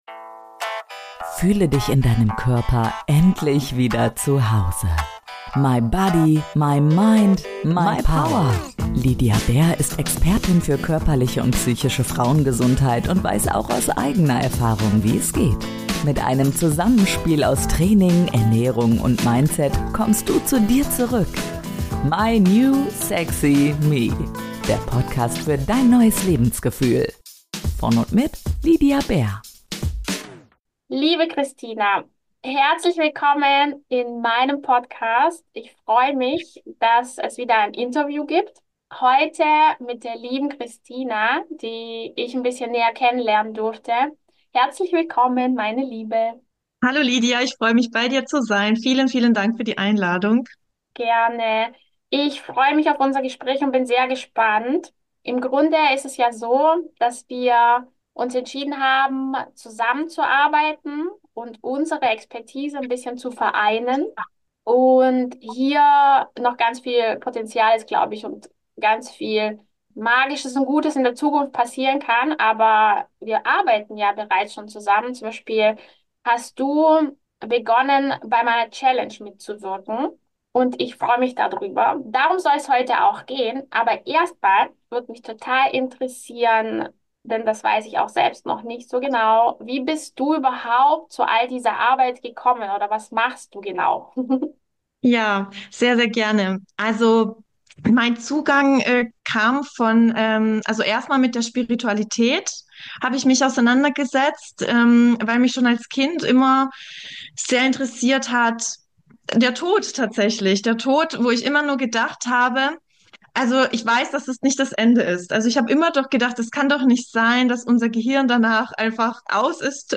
Willkommen zu einem neuen Interview!